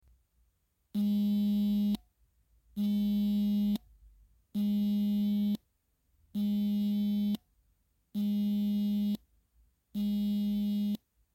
Venu 3 vibration clicking sound
the vibration motor on my Venu 3 makes an audible clicking sound after every vibration.
However, with the Venu 3, I can hear it even if my arm is resting on a table, for example.